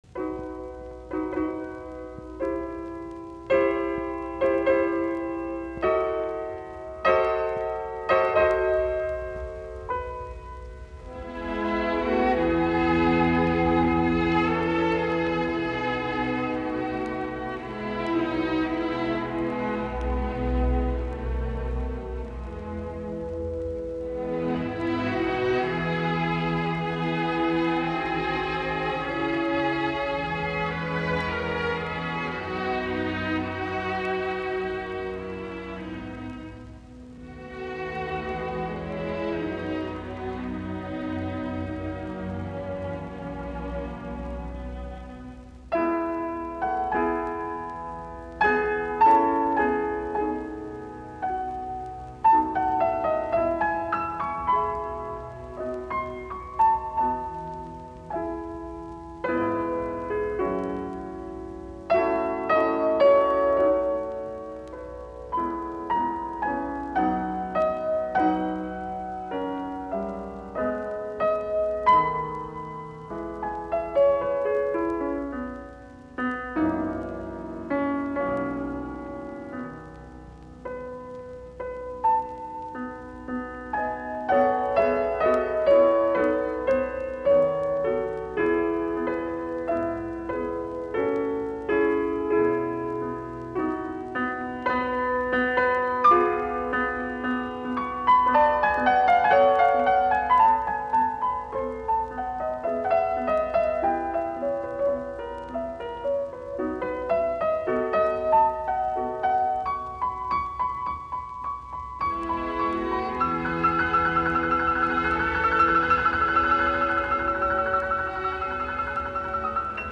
Konsertot, piano, ork., nro 1, op25, g-molli
Soitinnus: Piano, ork.